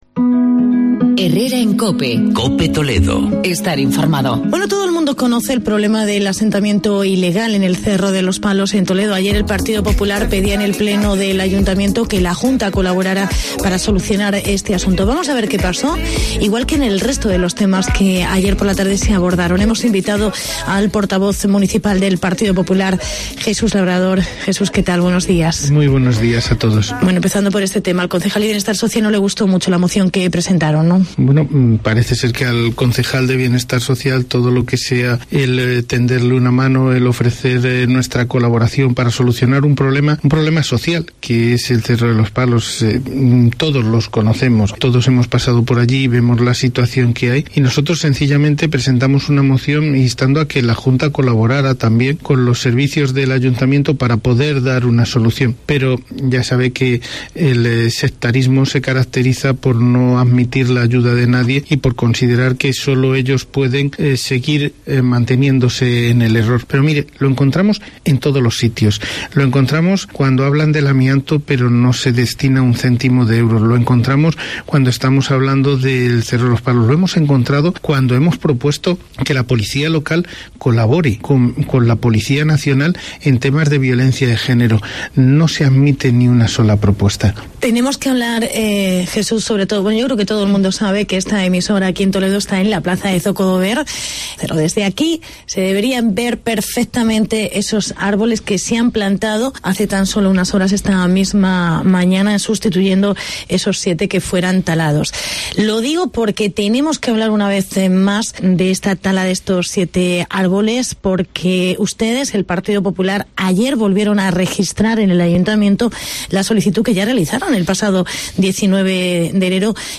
Entrevista con el portavoz del PP municipal.